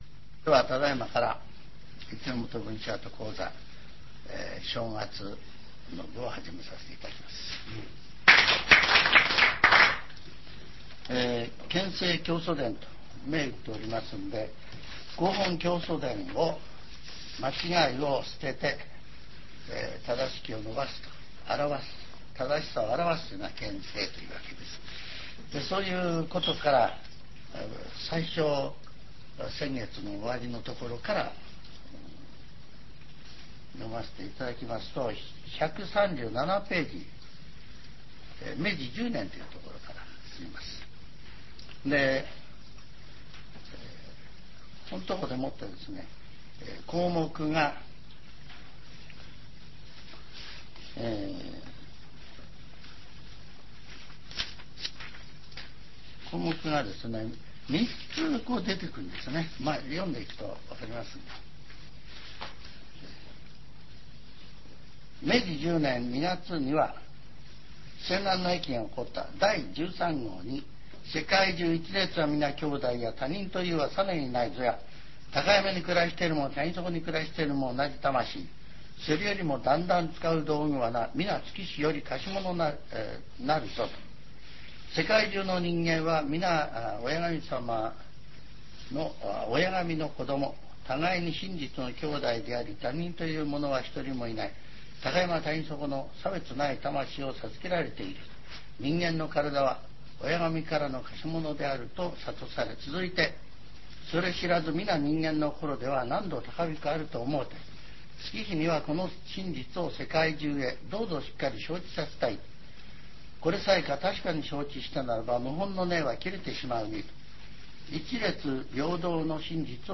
全70曲中29曲目 ジャンル: Speech